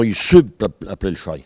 Il crie pour appeler le chien ( prononcer le cri )
Maraîchin